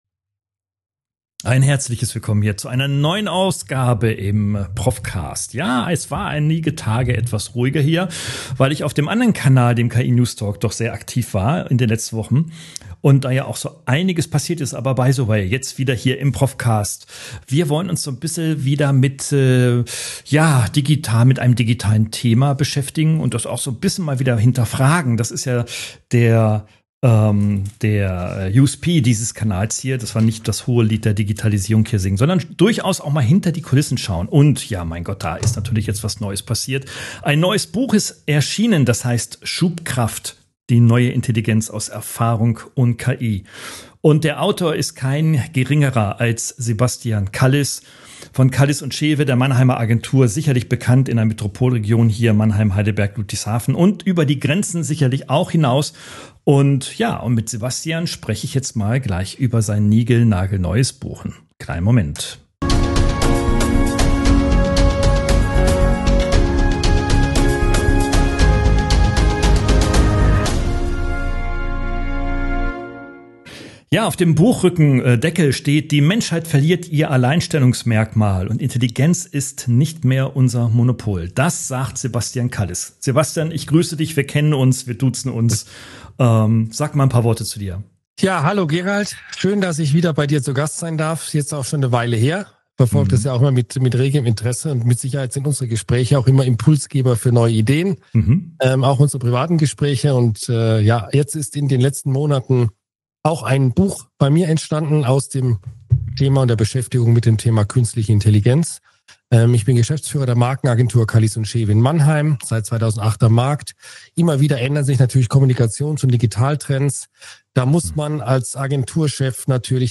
Podcast-Gespräch